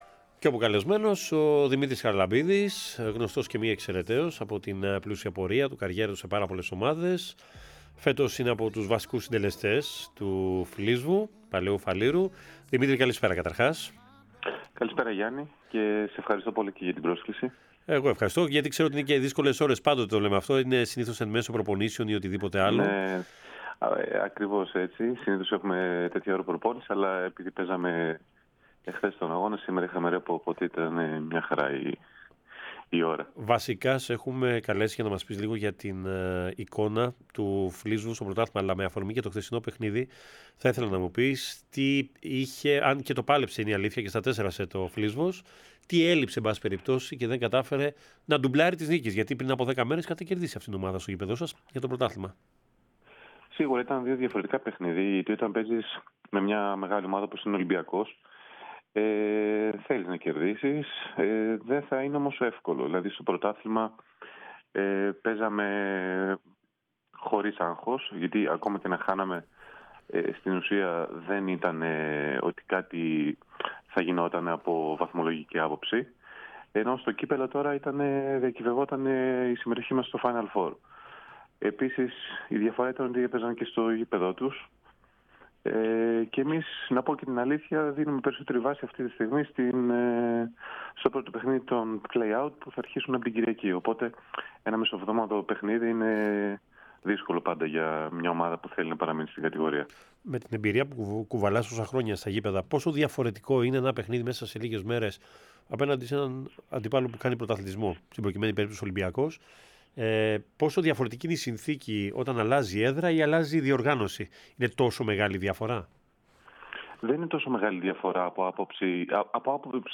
συνέντευξη στην εκπομπή “Match Point” της ΕΡΑ ΣΠΟΡ.